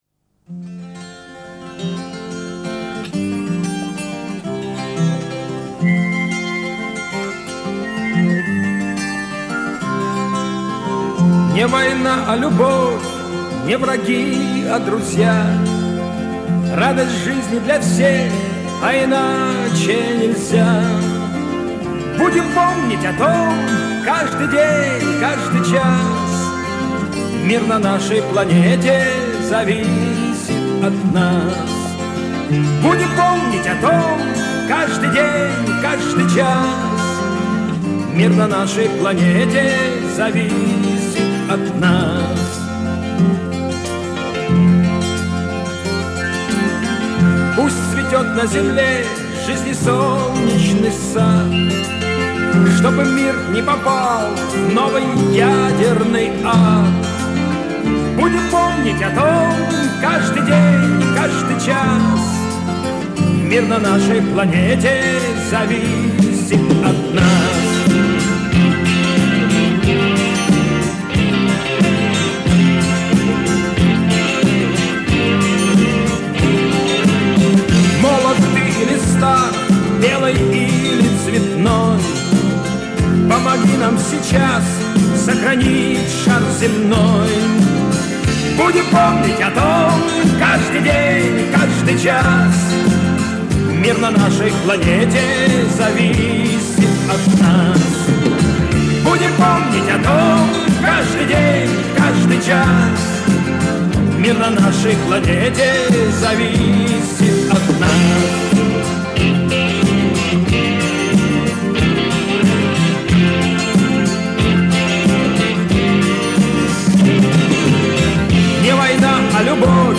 Качеством получше.